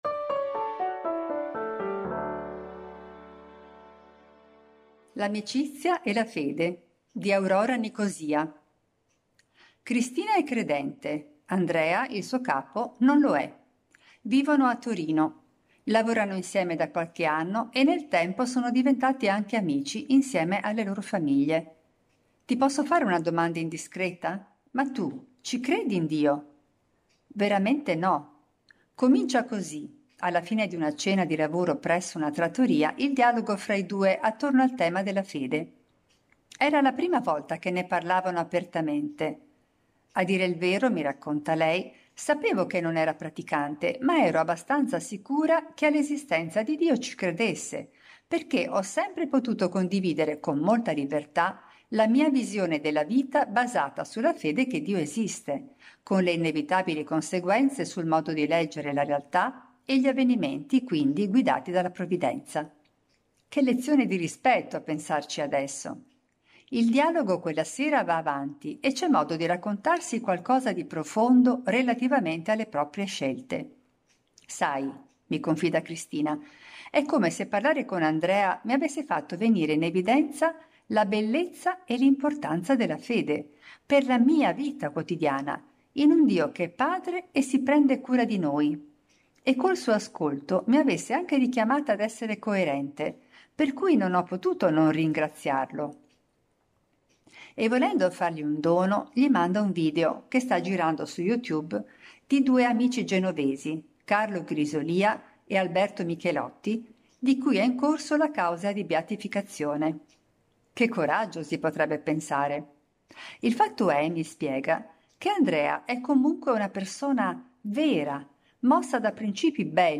Per ogni numero, ci sarà una selezione di 10 articoli letti dai nostri autori e collaboratori.
Al microfono, i nostri redattori e i nostri collaboratori.